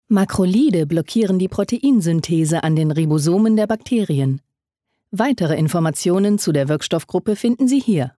Frische, helle, warme Stimme für E-Learning, Werbung, Guides und Imagefilme.
Sprechprobe: eLearning (Muttersprache):
Fresh, light, warm voice for e-learning, advertorials, guides and corporate films.